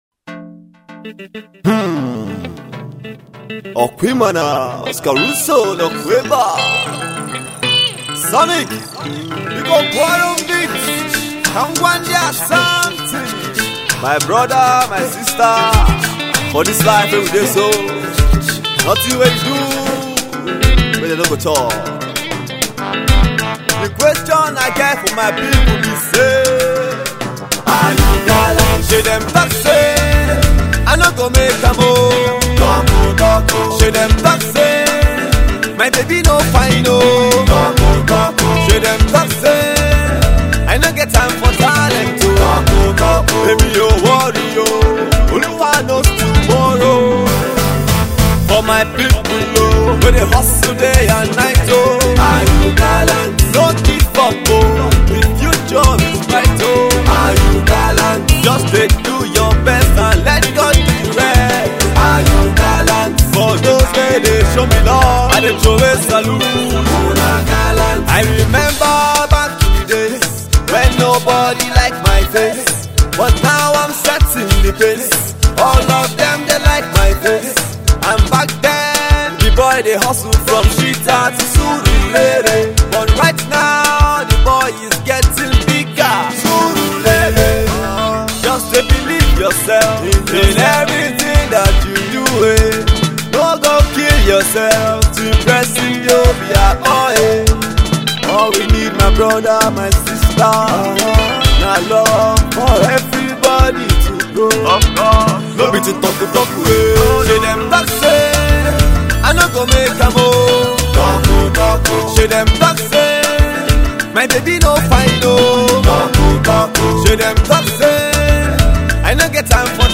Its a More afrobeat oriented Single